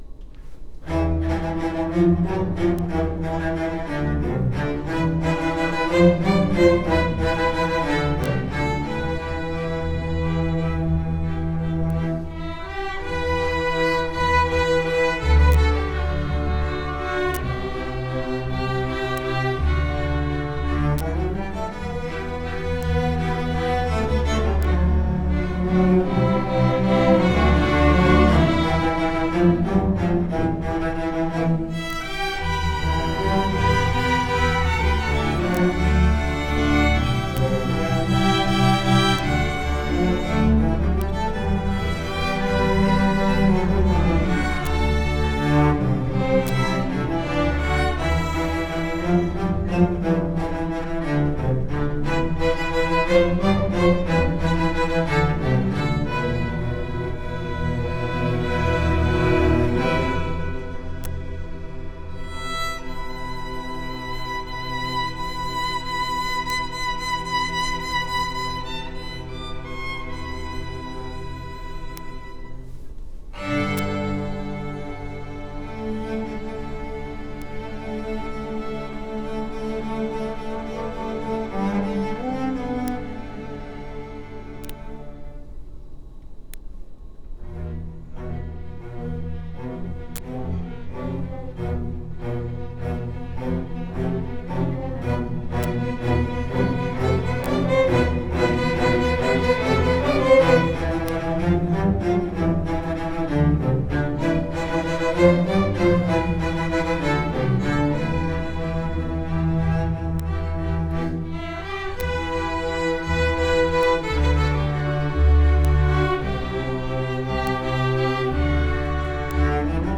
Orchestra District MPA 2020 – March 10 – 12 at Largo High School